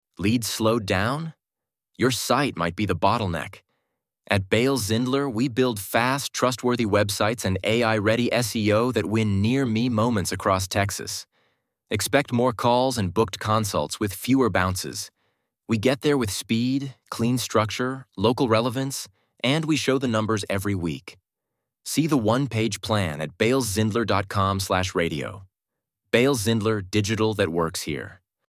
Bailes + Zindler — B2B lead-gen spot.
bailes_zindler_radio_ad_for_blog_2.mp3